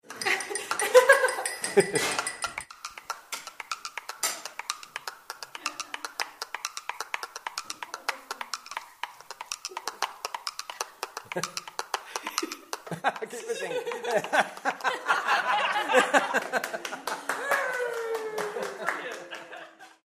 Vegetable percussion instrument using Alexanders